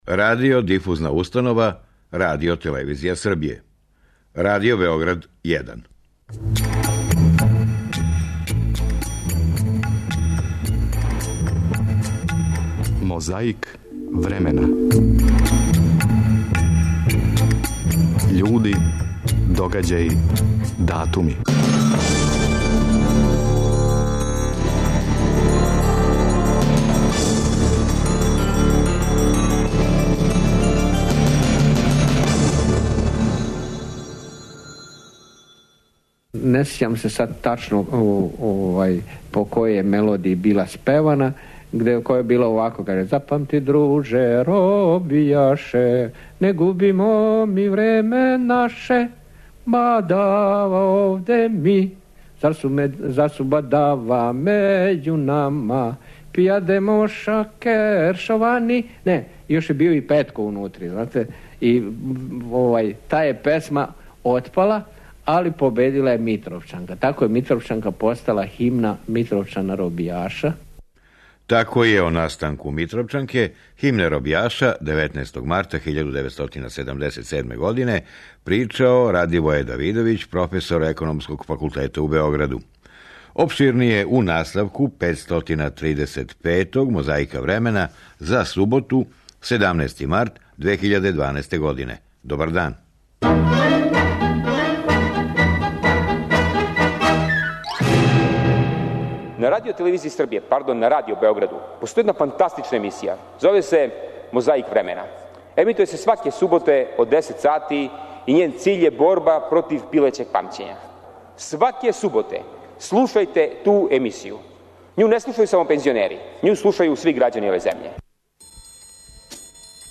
А и последња коцкица је говор Јосипа Броза Тита од 29. марта 1973. године када је посетио фабрику "Иво Лола Рибар" у Железнику.